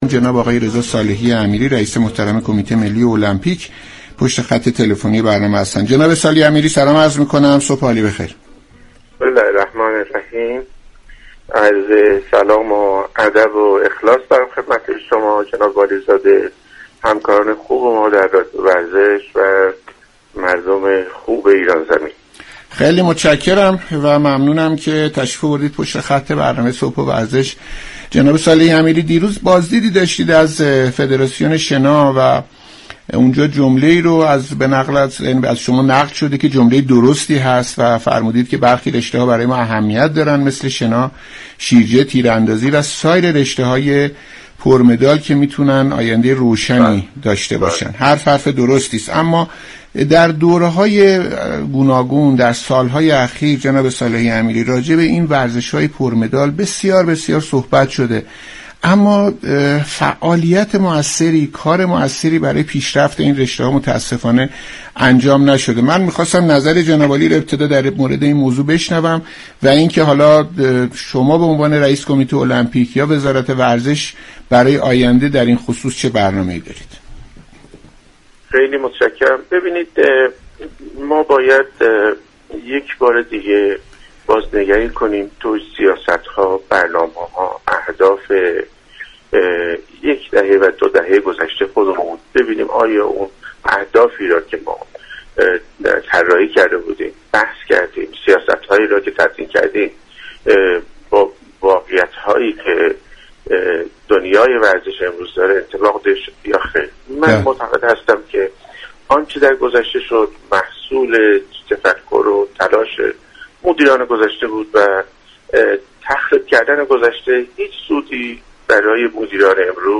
برنامه «صبح و ورزش» دوشنبه 19 خرداد در گفتگو با رضا صالحی امیری، رئیس كمیته ملی المپیك به بررسی تمهیدات اندیشیده شده برای موفقیت المپین ها پرداخت.